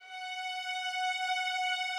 Added more instrument wavs
strings_066.wav